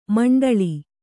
♪ maṇḍaḷi